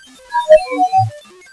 I took along my tape recorder and digital camera.
I don't know what this next lady spirit was talking about....
but you can clearly hear her say....